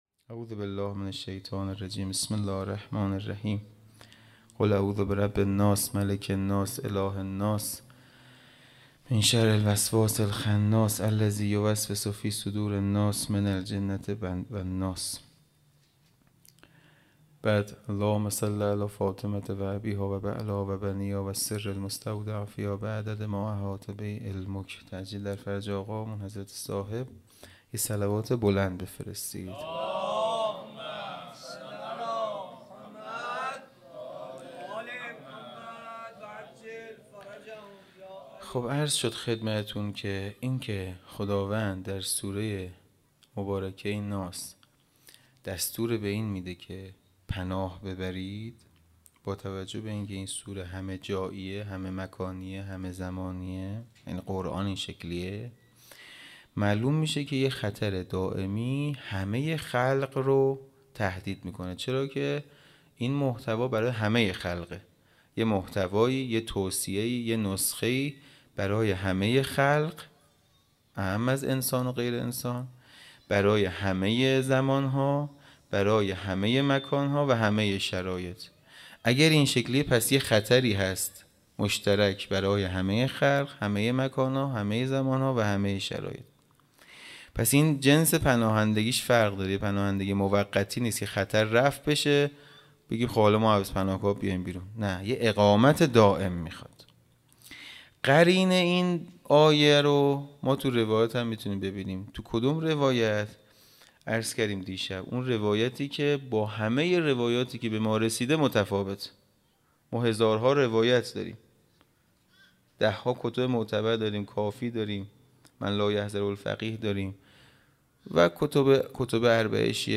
خیمه گاه - حسینیه کربلا - شب چهارم محرم-سخنرانیی
حسینیه کربلا